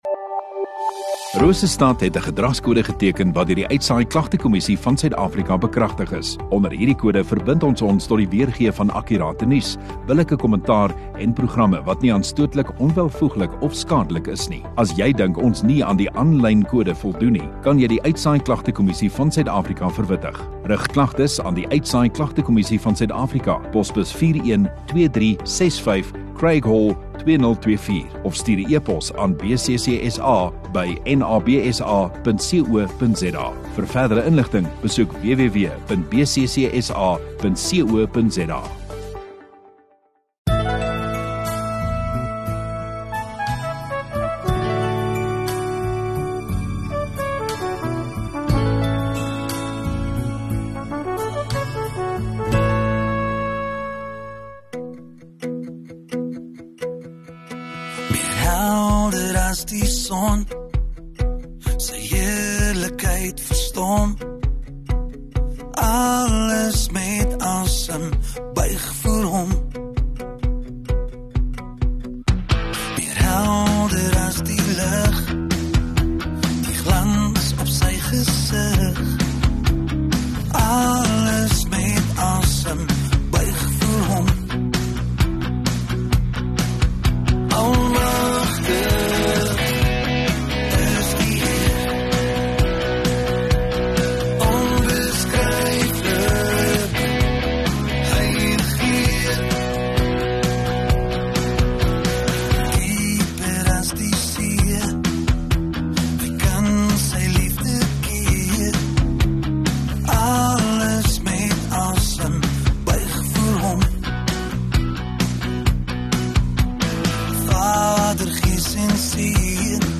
6 Jul Sondagoggend Erediens